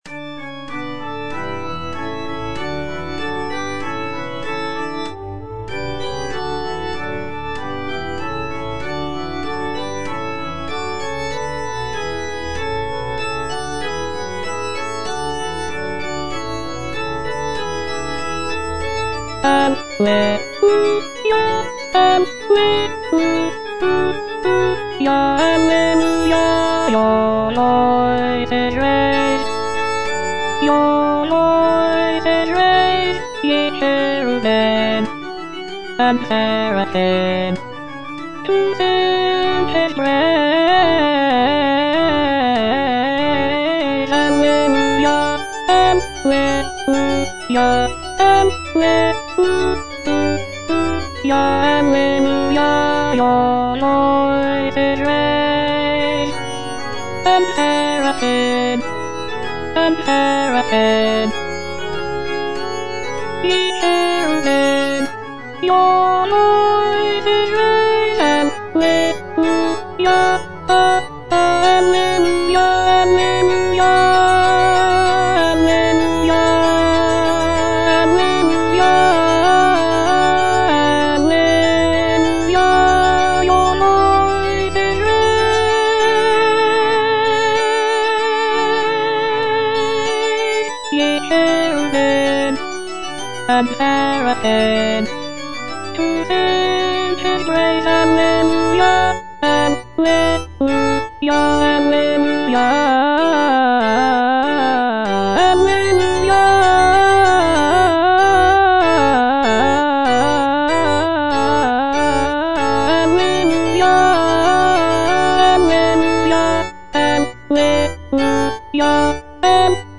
Choralplayer playing O praise the Lord with one consent - Chandos anthem no. 9 HWV254 (A = 415 Hz) by G.F. Händel based on the edition CPDL #08760
G.F. HÄNDEL - O PRAISE THE LORD WITH ONE CONSENT - CHANDOS ANTHEM NO.9 HWV254 (A = 415 Hz) Your voices raise - Alto (Voice with metronome) Ads stop: auto-stop
The work is typically performed with historical performance practices in mind, including the use of a lower tuning of A=415 Hz to replicate the sound of Handel's time.